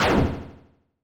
snd_heavyswing.wav